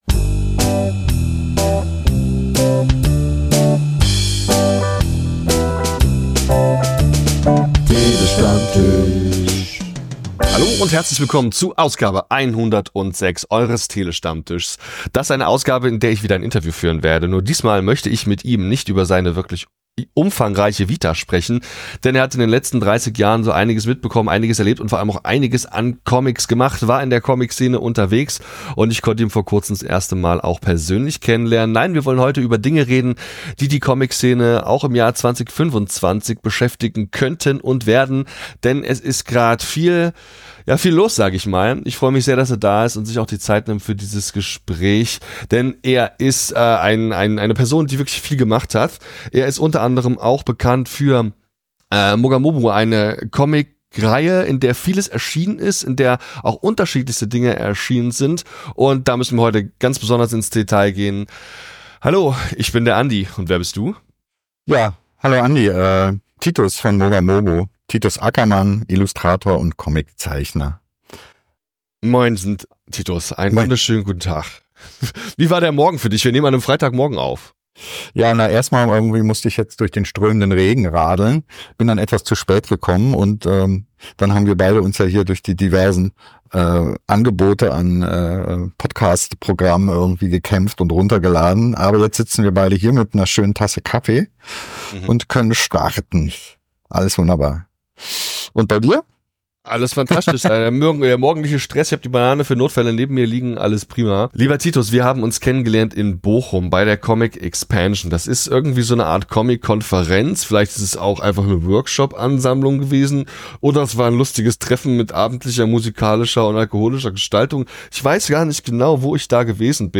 Der Tele-Stammtisch - Comictalks & Interviews Podcast